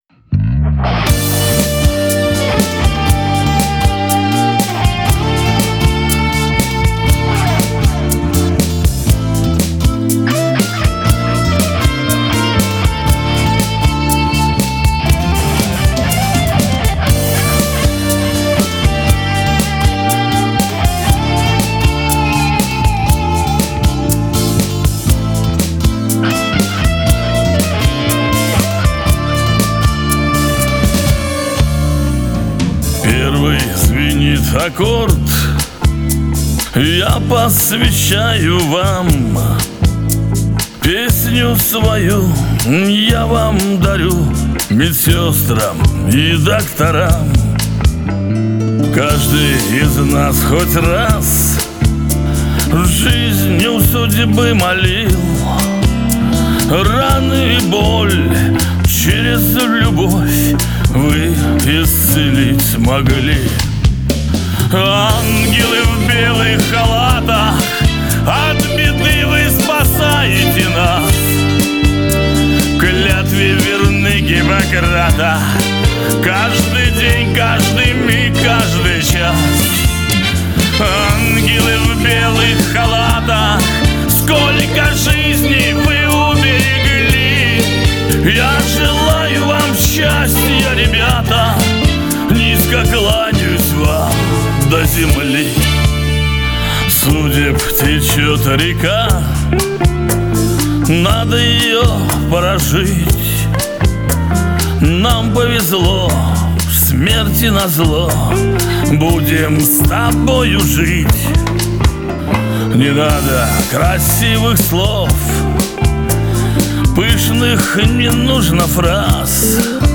Небольшая подборка прекрасного шансонье...